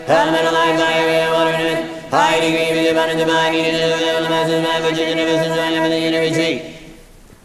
in the form of superfast cantillation